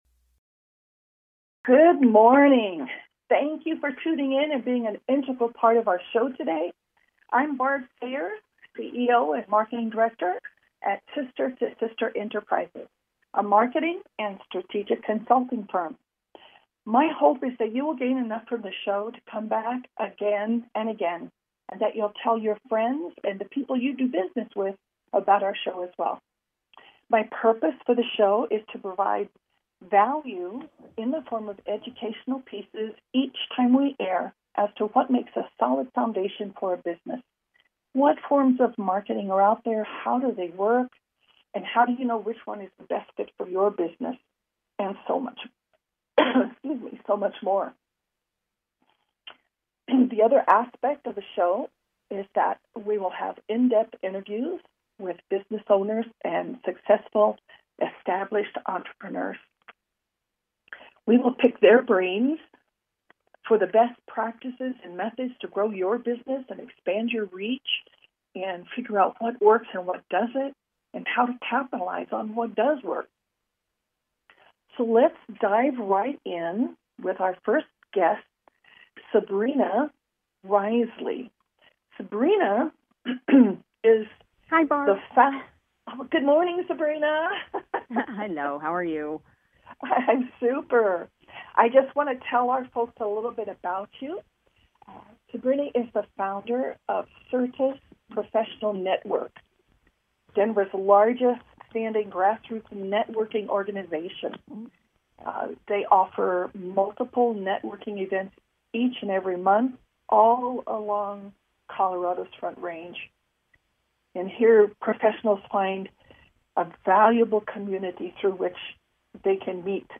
Bizz Buzz is a talk show, crafted to provide unique, powerful marketing techniques that can positively impact your businesses bottom line, in sales and customer acquisition.
Call-ins encouraged!